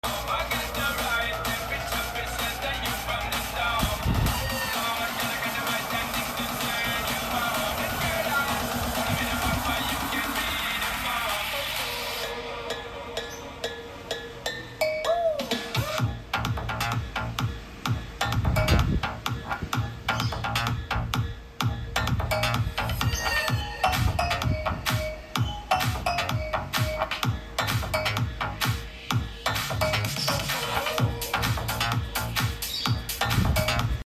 Tes tv polytron sound bar sound effects free download